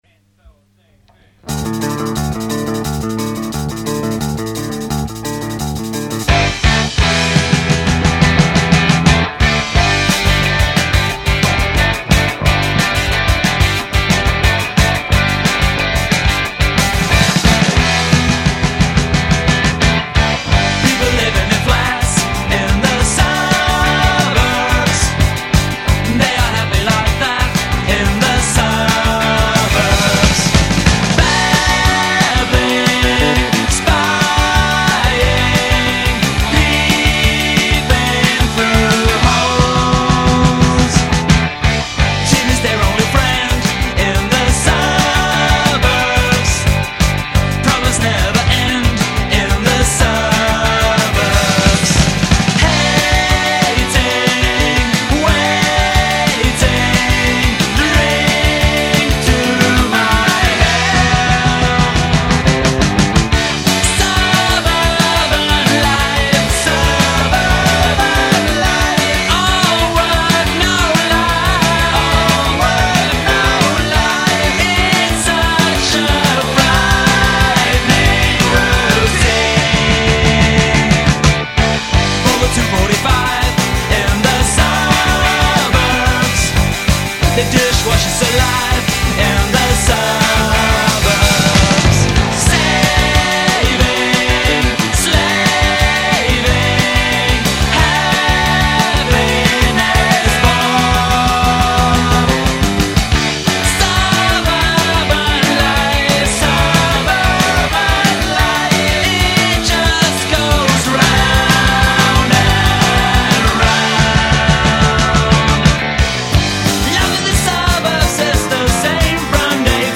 Demo -81